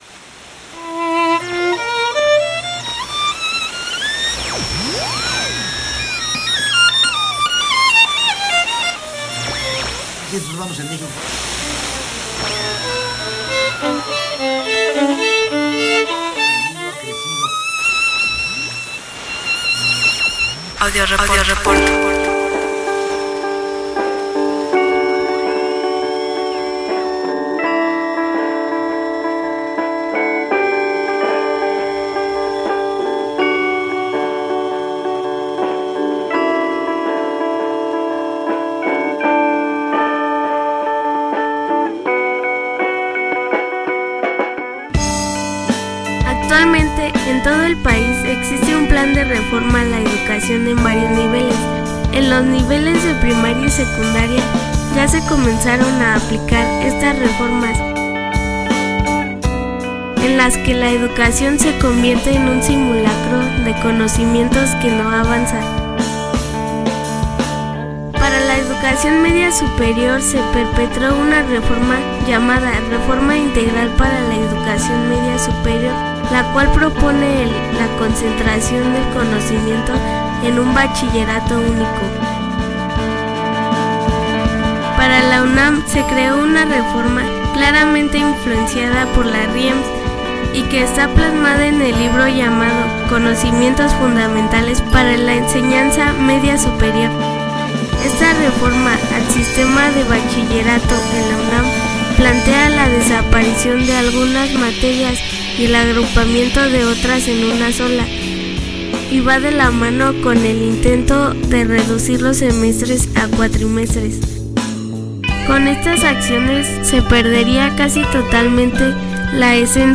52_Audioreporte_marcha_cchs.mp3